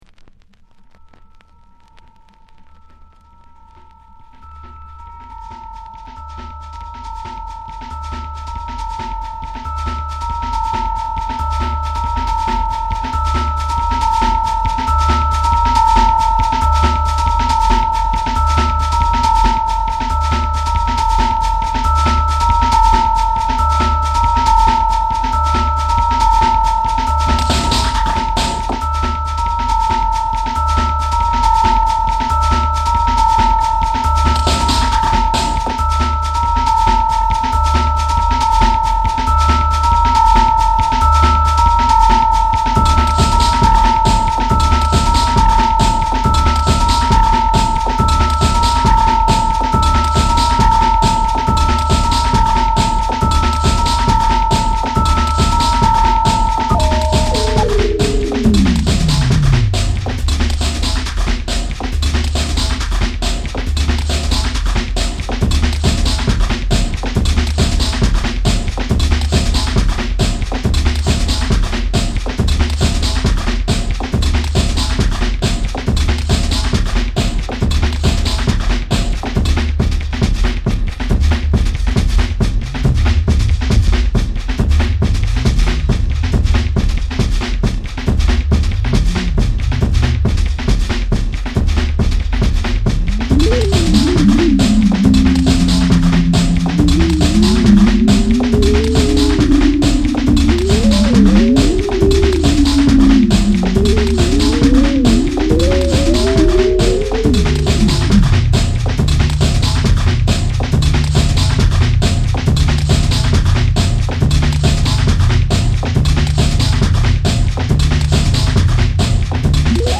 この時代ならではの音使いや豪快な展開、それでいて職人気質すぎる几帳面さも垣間見えるのが長く支持される所以なんでしょうね。